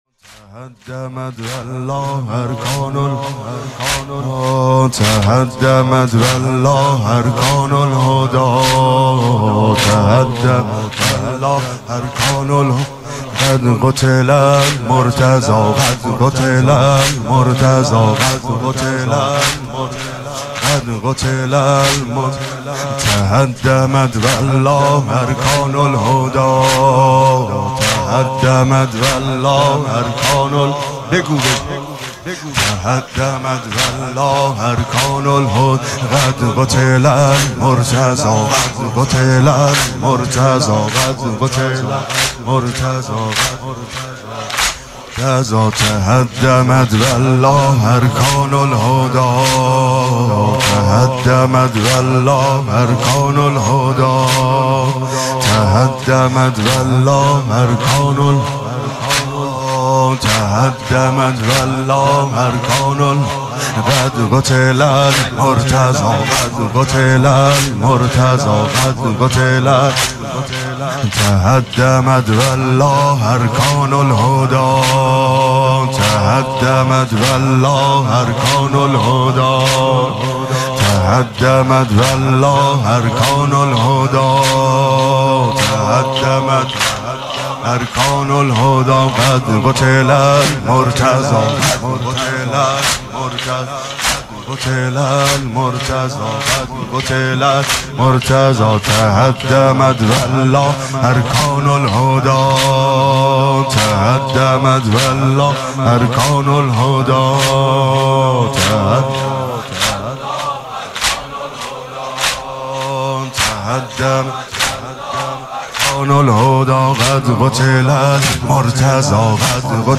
نوحه «تهدمت والله ارکان الهدی» با صدای عبدالرضا هلالی به مناسبت شهادت حضرت علی (ع).